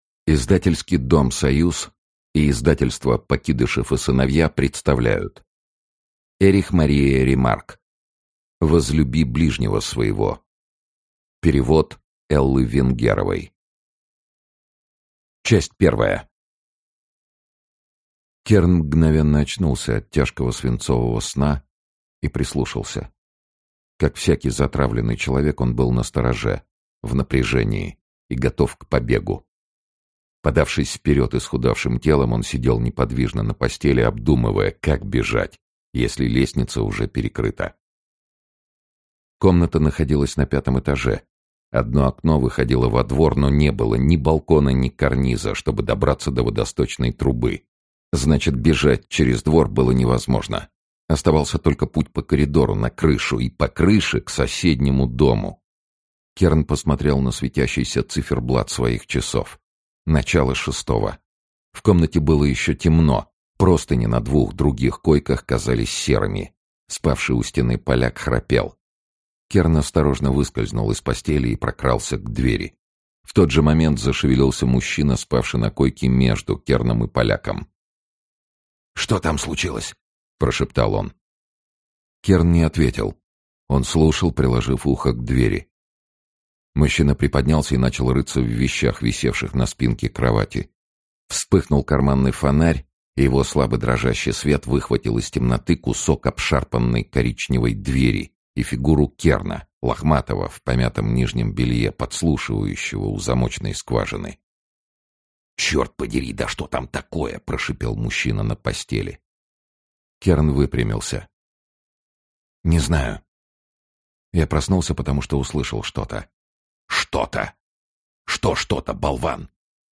Аудиокнига Возлюби ближнего своего - купить, скачать и слушать онлайн | КнигоПоиск